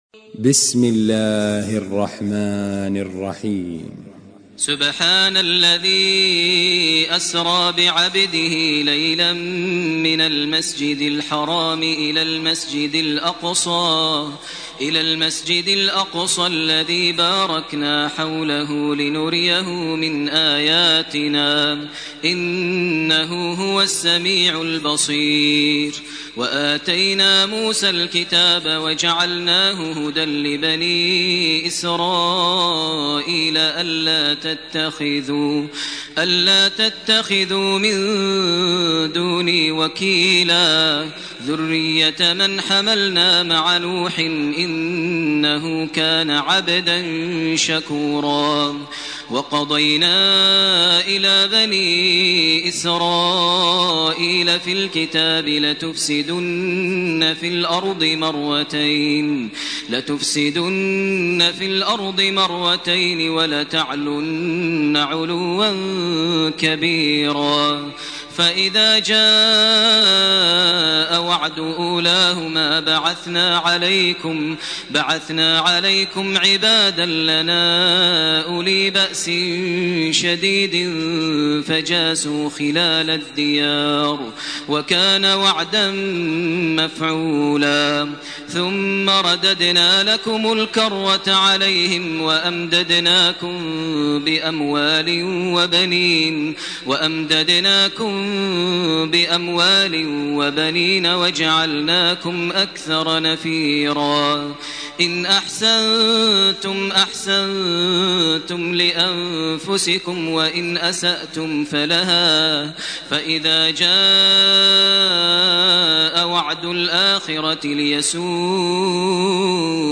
سورة الإسراء 1 - 100 > تراويح ١٤٢٨ > التراويح - تلاوات ماهر المعيقلي